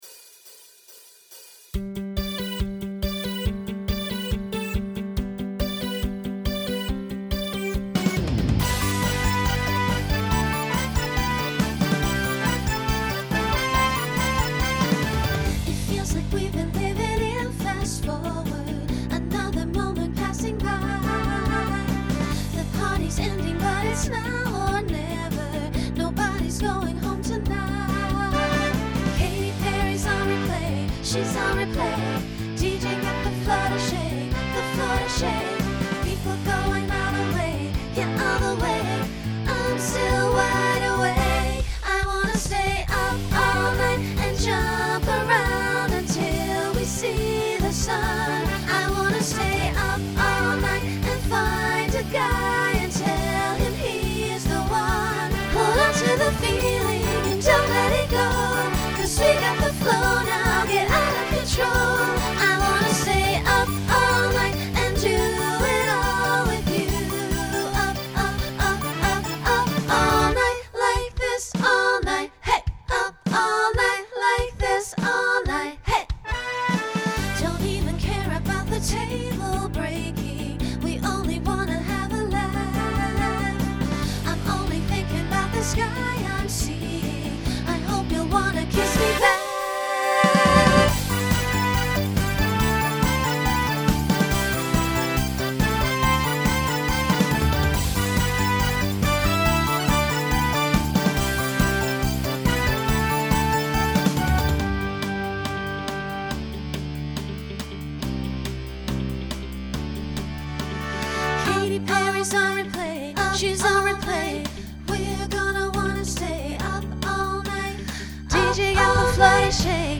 Genre Pop/Dance Instrumental combo
Voicing SSA